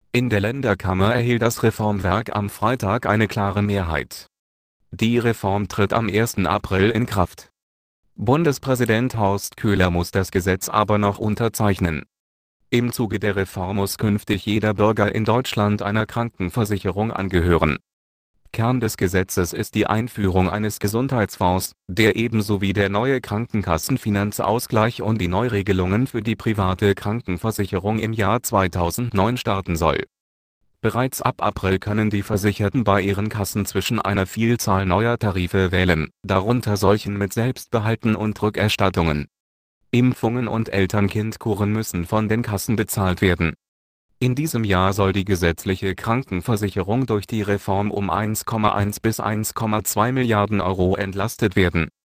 Texte de d�monstration
Nuance RealSpeak; distribu� sur le site de Nextup Technology; homme; allemand